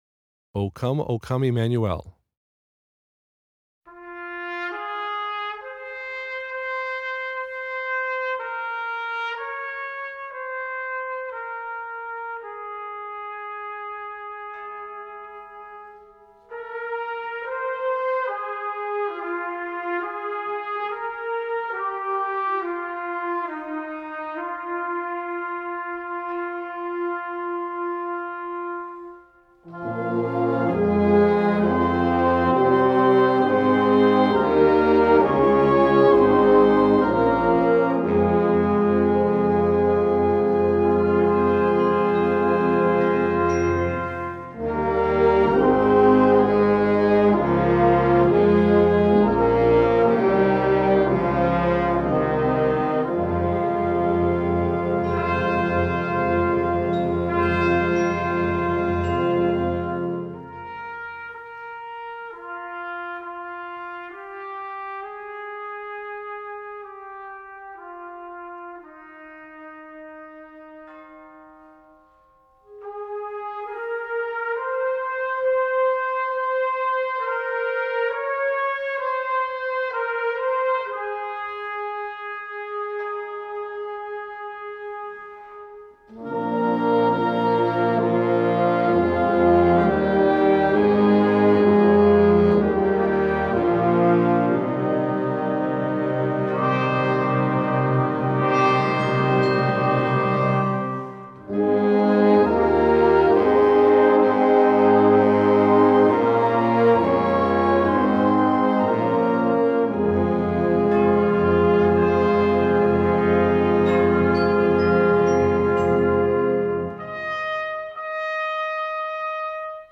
Concert Band
Traditional